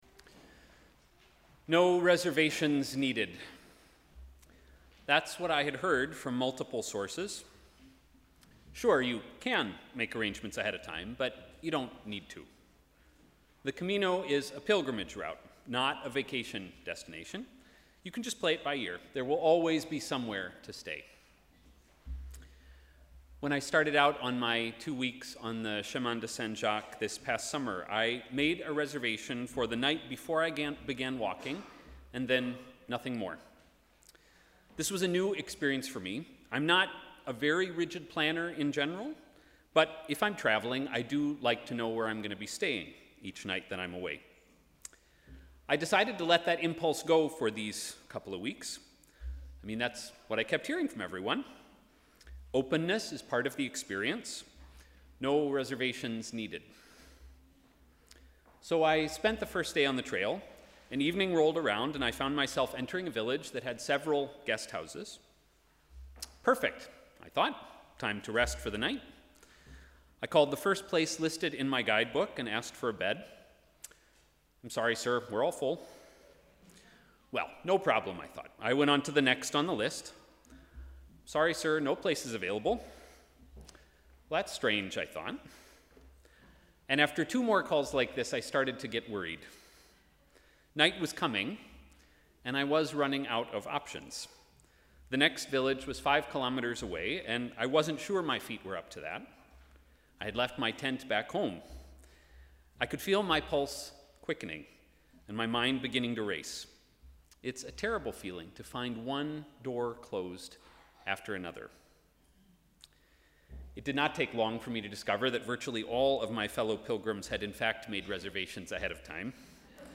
Sermon: ‘No room at the inn’